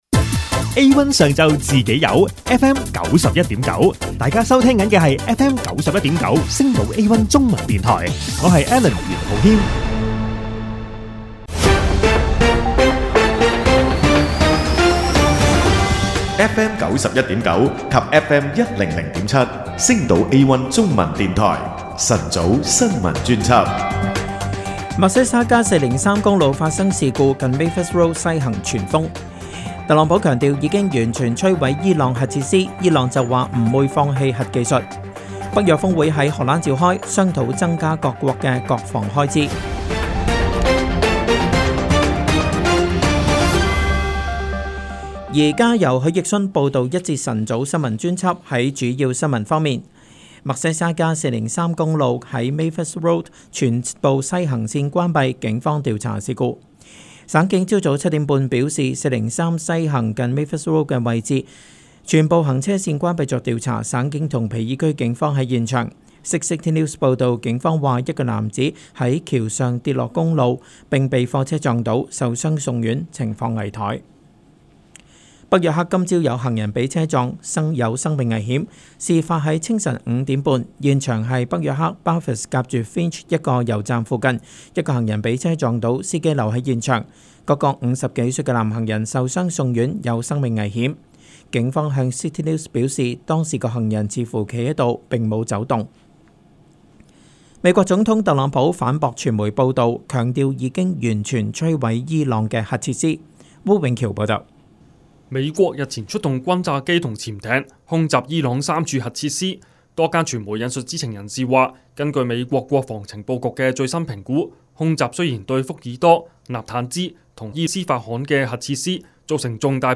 【有聲新聞】星島A1中文電台 晨早新聞專輯